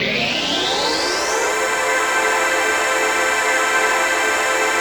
ATMOPAD32 -LR.wav